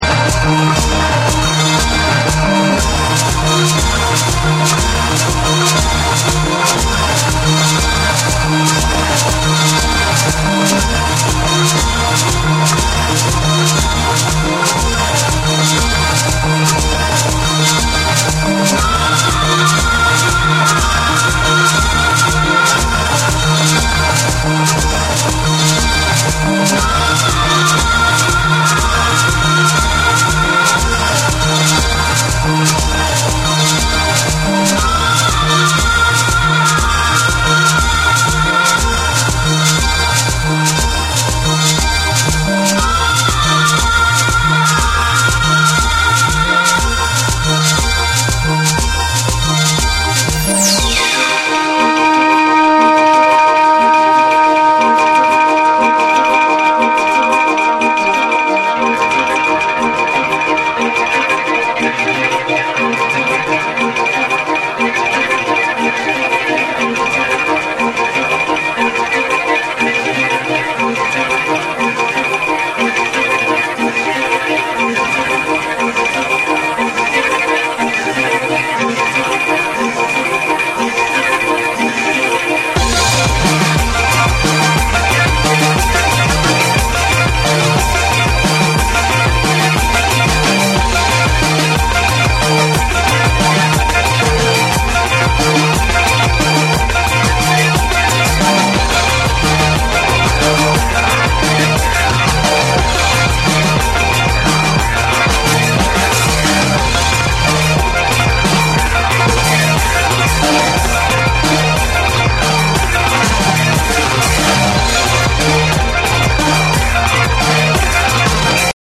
ウエスタン風味のサイケデリック・フォーク・ロック
TECHNO & HOUSE